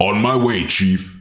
OC Grenadier Voice Over
Original voice over for a grenadier type unit.